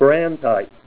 Help on Name Pronunciation: Name Pronunciation: Brandtite + Pronunciation
Say BRANDTITE Help on Synonym: Synonym: ICSD 36235   PDF 29-348